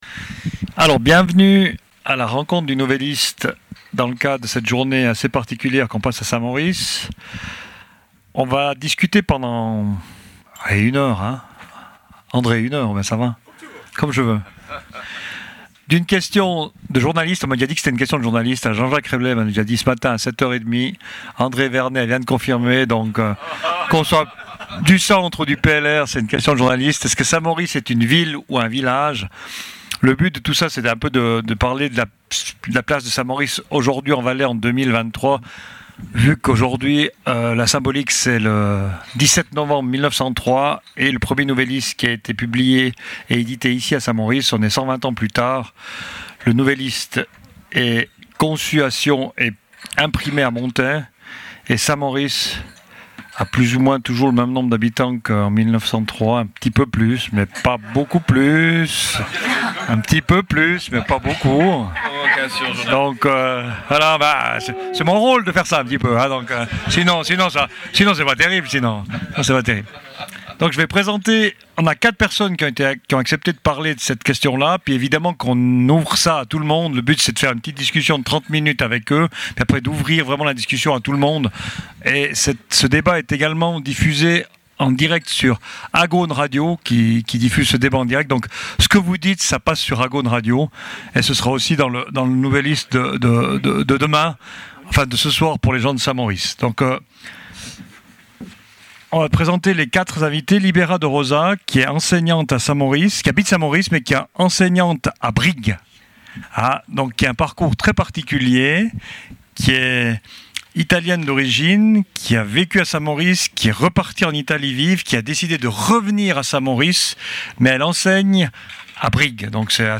Le débat des Rencontres du Nouvelliste
et la participation du public présent à la Dent-du-Midi.